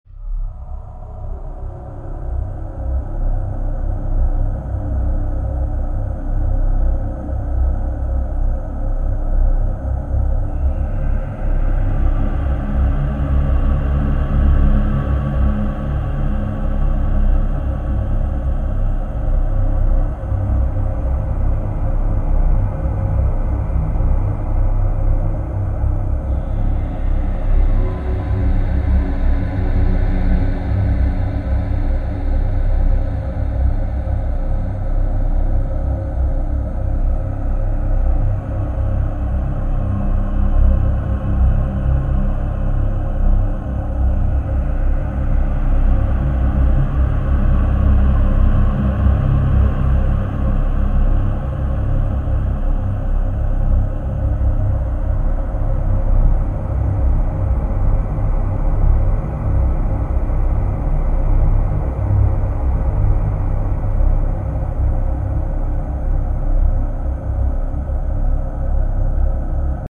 I'm trying to capture the sound of despair
Dark Loop.mp3